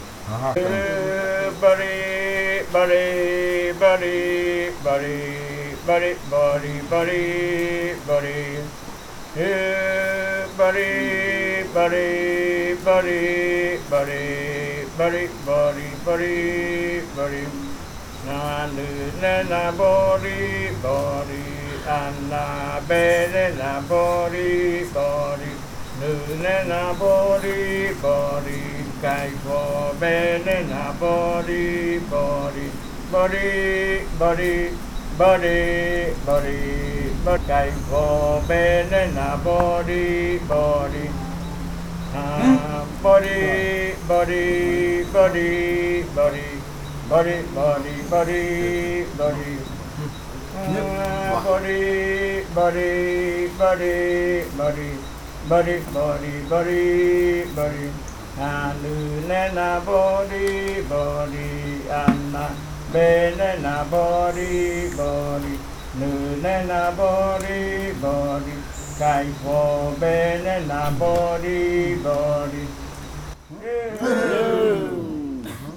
Leticia, Amazonas, (Colombia)
Grupo de danza Kaɨ Komuiya Uai
Canto de fakariya de la variante muruikɨ (cantos de la parte de arriba). En el canto, al decir "bori bori" (relampaguea) se está refiriendo a la totuma de ambil líquido (yerabɨ).
Fakariya chant of the Muruikɨ variant (Upriver chants).